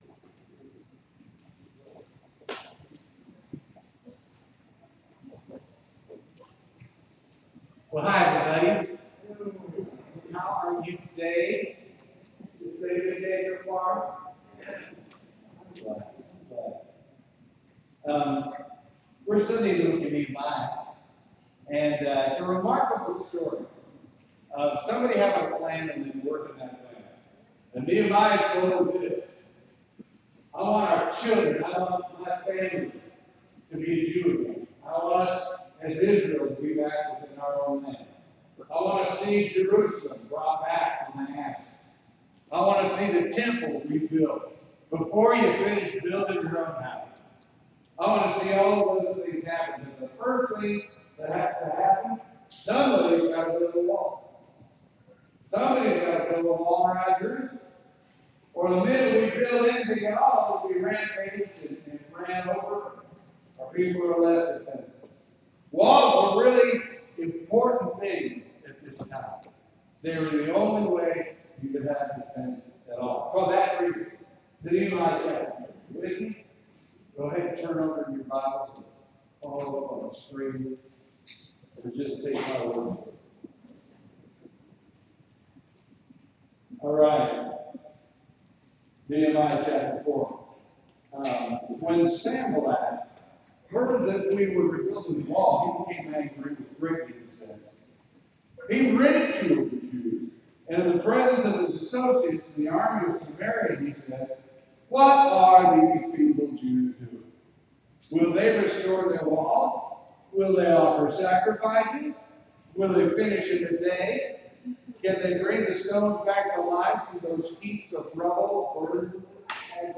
Sermon: Nehemiah 4 – IGNITE-TRANSFORM-REFLECT-SHINE